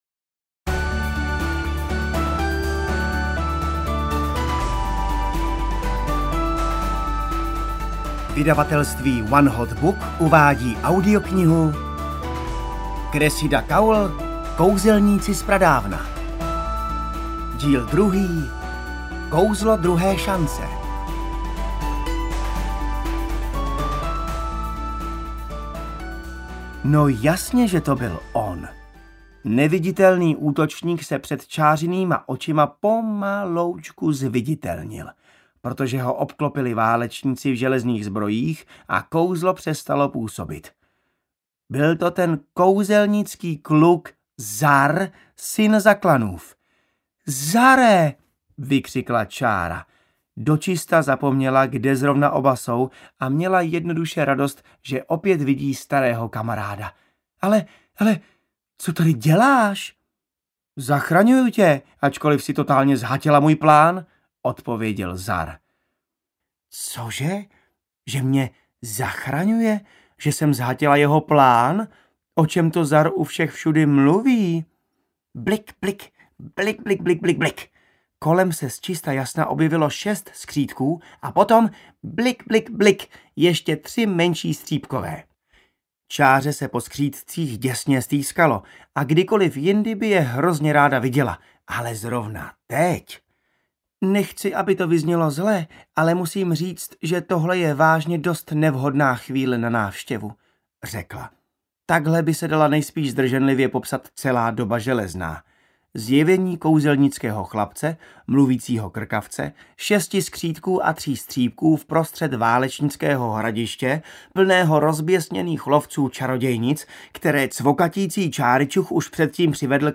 Kouzelníci z pradávna 2 - Kouzlo druhé šance audiokniha
Ukázka z knihy
• InterpretJan Maxián